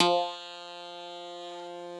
genesis_bass_041.wav